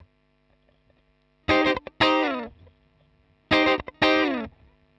120_Guitar_funky_riff_C_2.wav